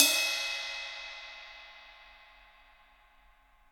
MIX RIDE 2.wav